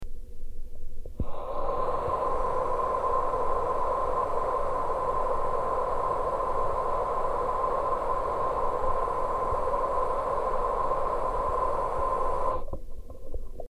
Geophone is an omnidirectional contact microphone.
Water tap
Water-tap.mp3